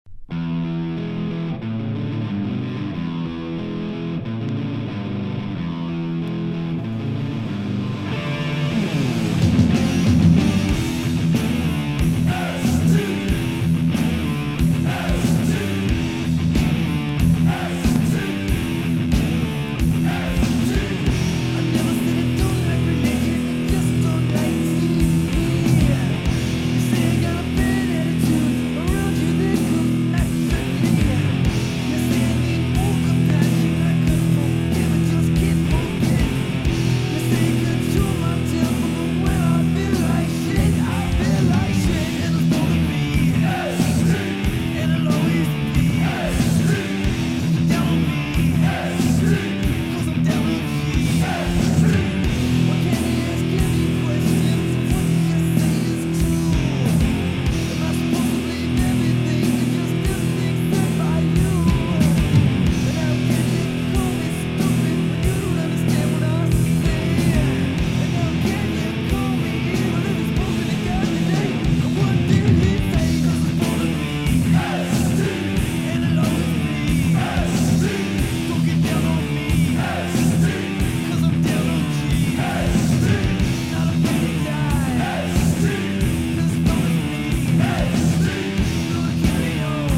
重厚なドラムとギターに「ST」という掛け声が響くアンセム